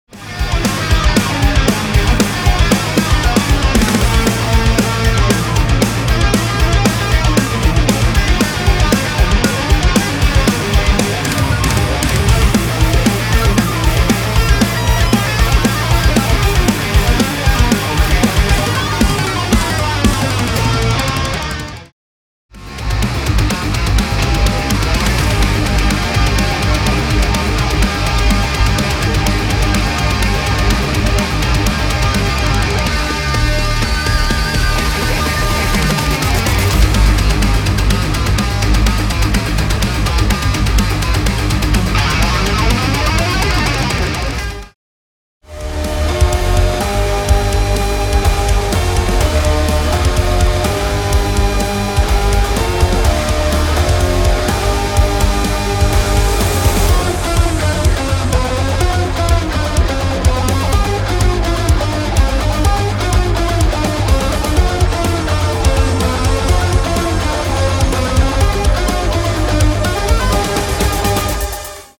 Soundtrack Sample